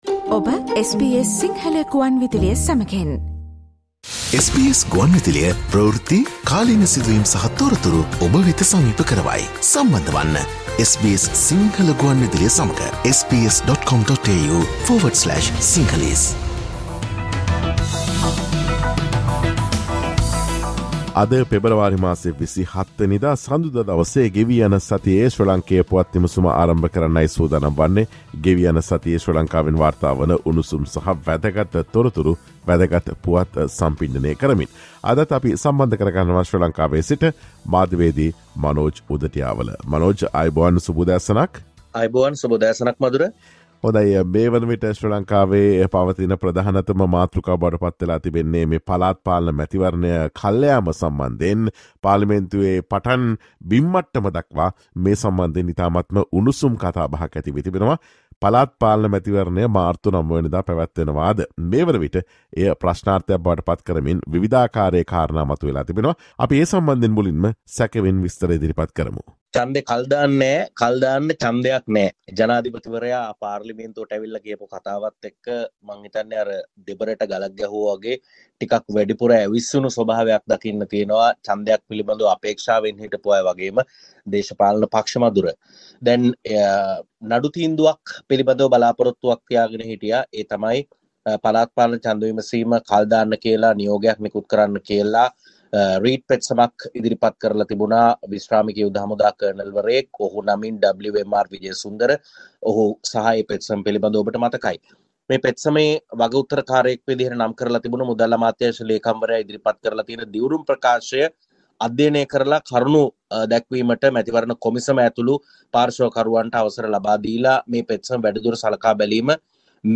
Sri Lanka’s Weekly Political Highlights